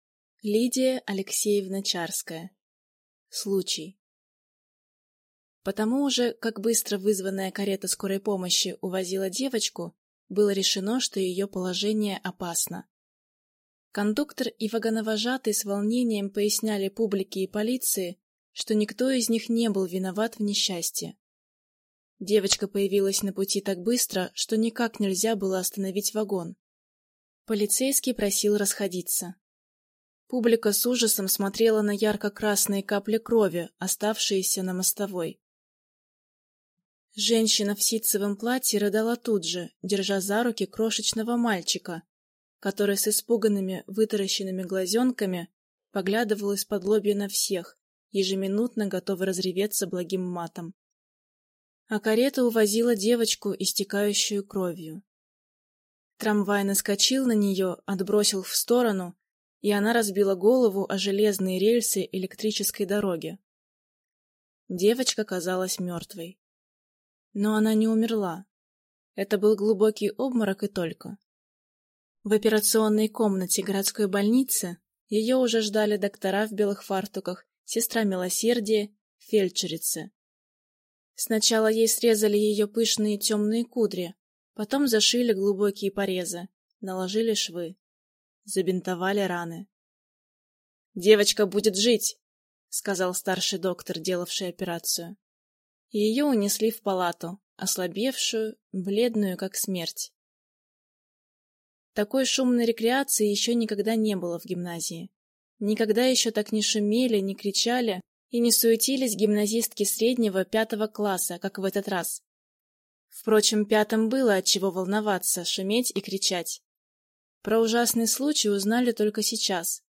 Аудиокнига Случай | Библиотека аудиокниг